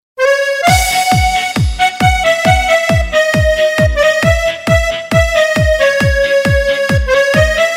VST Гитара, чтобы на слабую долю играла